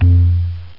Conga Sound Effect
Download a high-quality conga sound effect.
conga.mp3